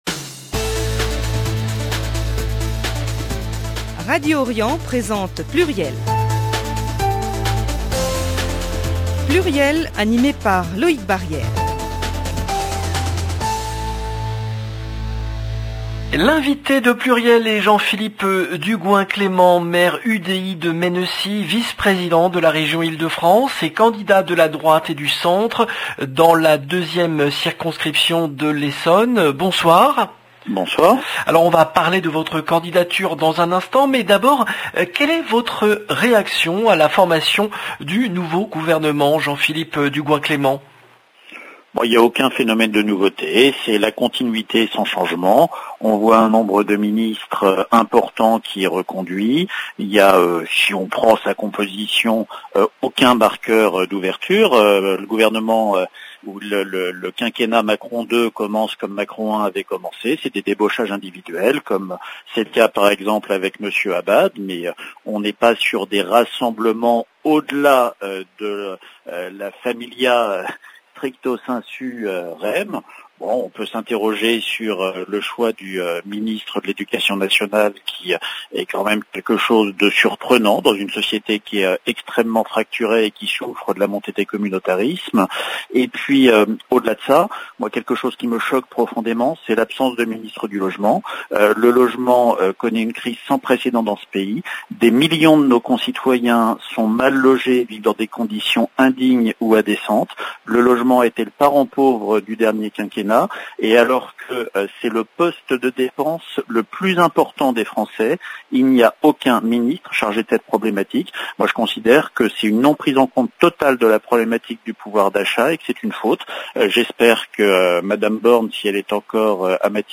L’invité de PLURIEL est Jean-Philippe Dugoin-Clément , maire UDI de Mennecy, vice-président de la Région Ile-de-France et candidat de la droite et du centre dans la 2e circonscription de l’Essonne